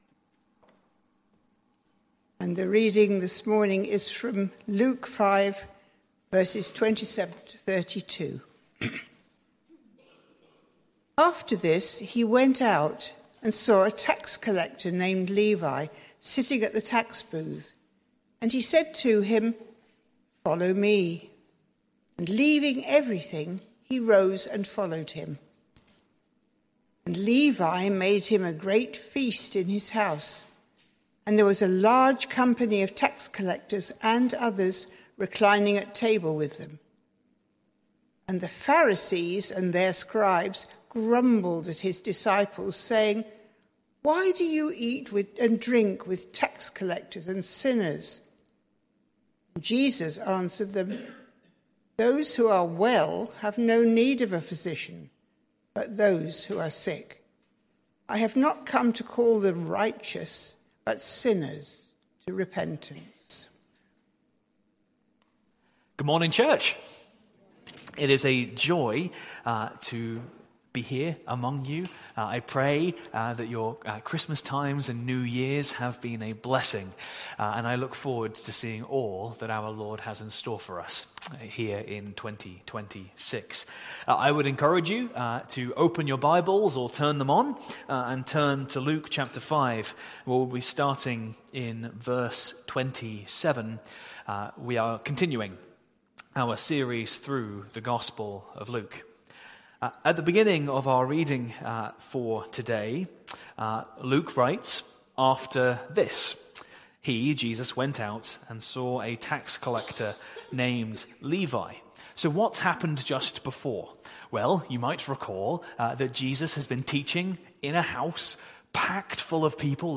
Sermon Series: Luke’s Gospel